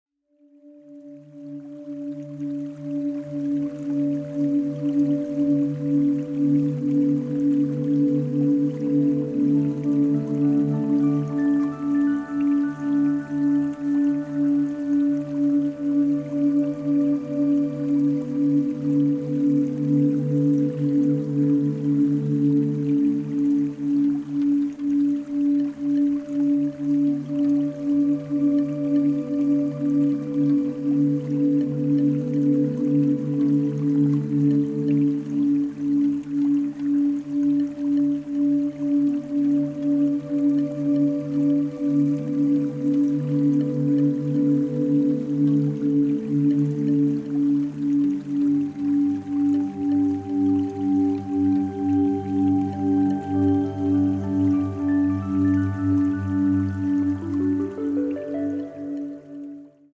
Música para sanar con la frecuencia 285 Hz
binaural Delta